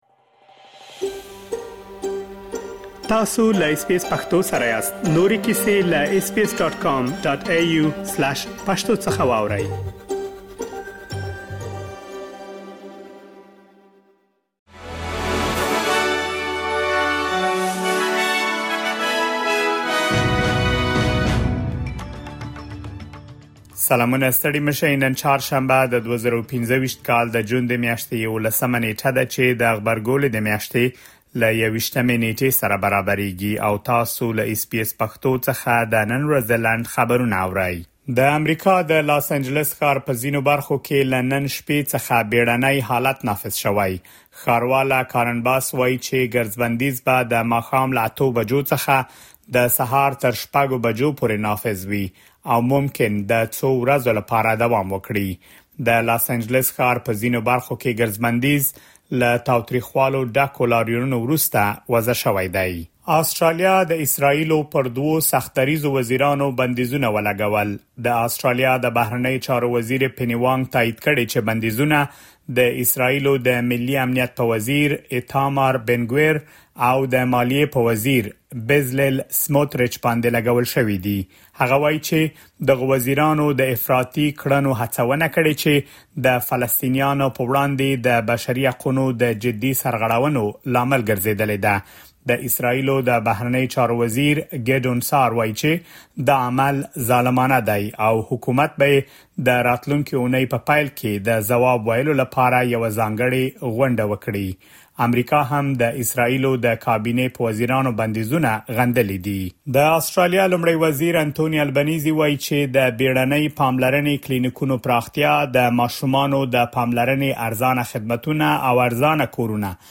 د اس بي اس پښتو د نن ورځې لنډ خبرونه | ۱۱ جون ۲۰۲۵